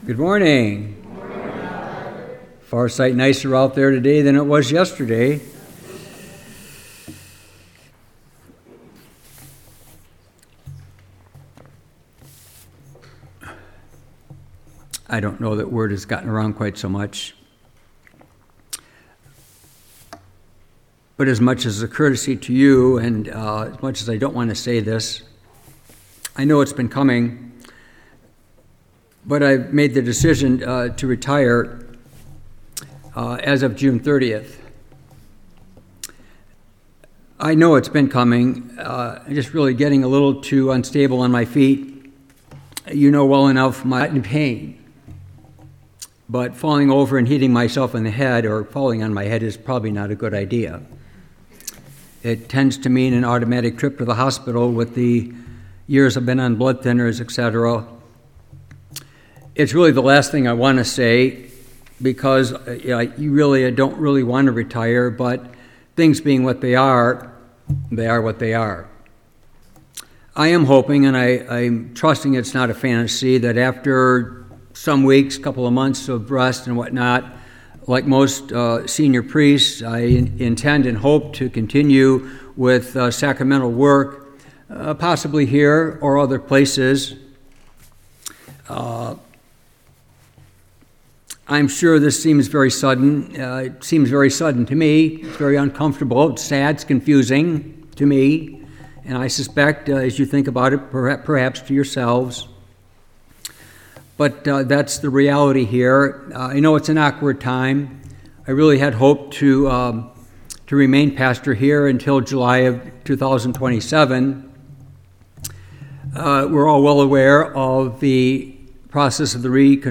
Homily , April 19, 2026